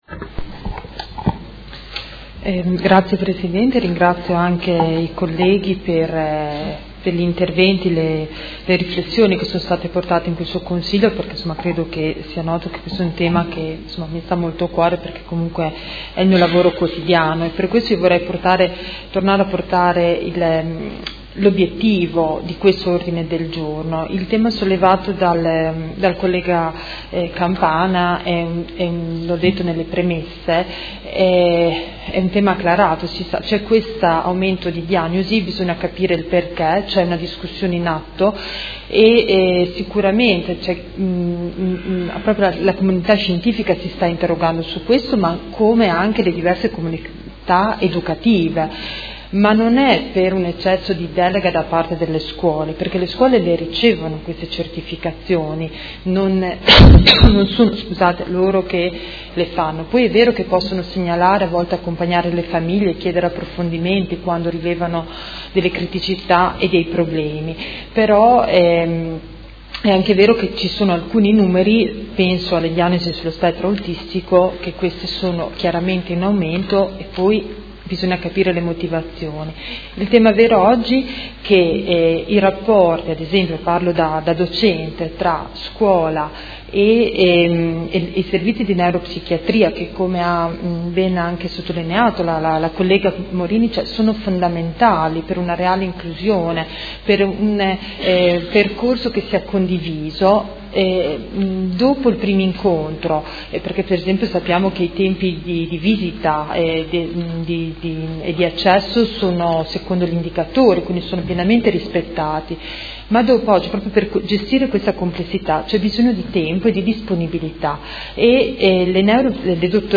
Dibattito su Ordine del Giorno presentato dai Consiglieri Baracchi, Pacchioni, Fasano, Arletti, Forghieri, Lenzini, Di Padova, Venturelli, Morini, Poggi, Liotti e De Lillo (PD)avente per oggetto: Verifica e approfondimento progetti d’inclusione alunni e alunne con disabilità